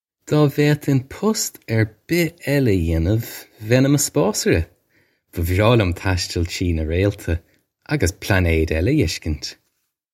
Pronunciation for how to say
Daw vade-hin pust urr bih ella uh yay-nuv, vay-in immuh spaw-surra. Buh vraw lyum tash-till chree nuh rayl-tuh auggus plaw-nayd ella uh esh-kint!
This is an approximate phonetic pronunciation of the phrase.